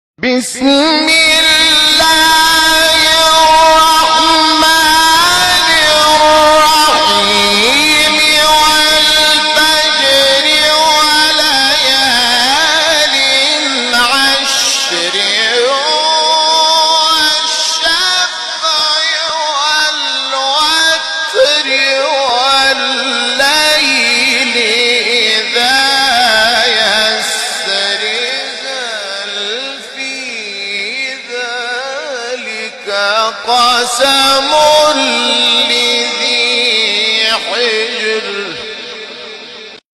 تلاوت سوره فجر جدید محمود شحات | نغمات قرآن
سوره:‌ فجر آیه:‌ 1-5 استاد:‌ محمود شحات مقام: بیات وَالْفَجْرِ ﴿١﴾ وَلَيَالٍ عَشْرٍ ﴿٢﴾ وَالشَّفْعِ وَالْوَتْرِ ﴿٣﴾ وَاللَّيْلِ إِذَا يَسْرِ ﴿٤﴾ قبلی بعدی